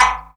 DR - redd perc.wav